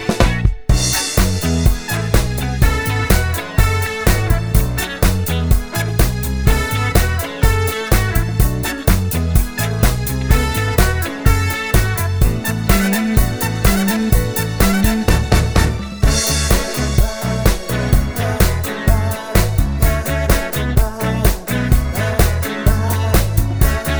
no Backing Vocals Disco 3:39 Buy £1.50